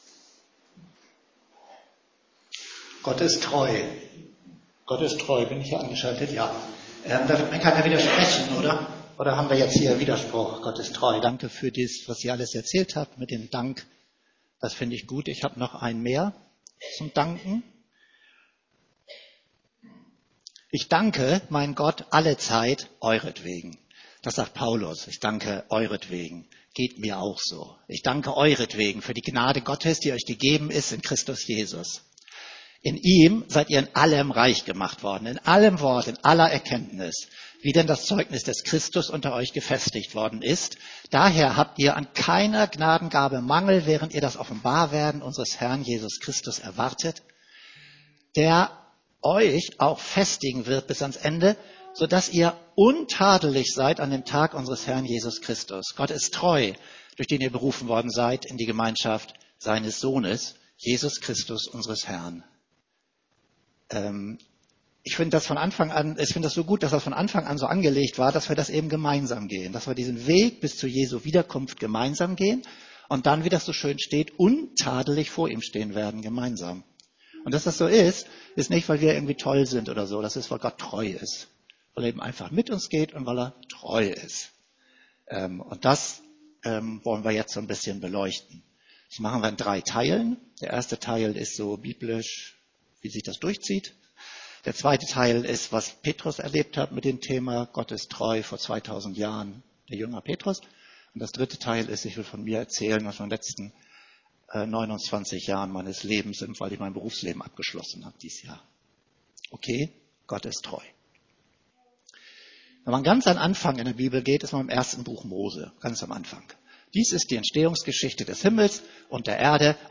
Erntedankgottesdienst